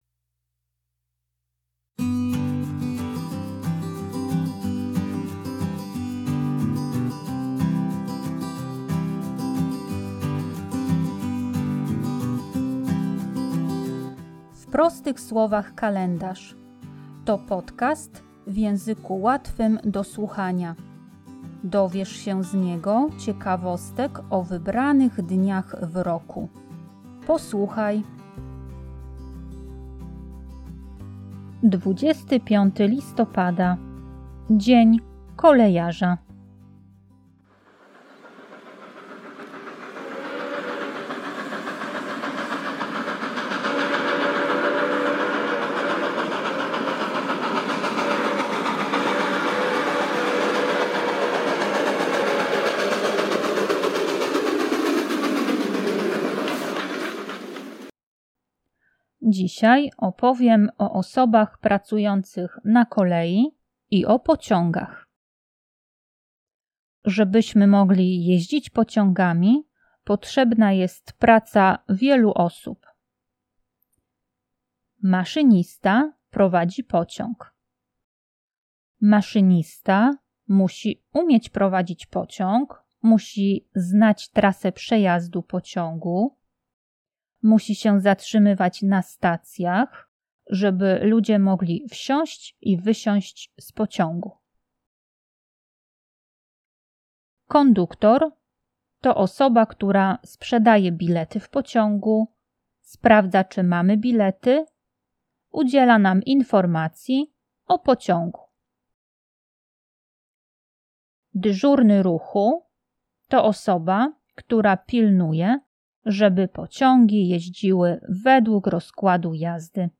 Tekst i lektorka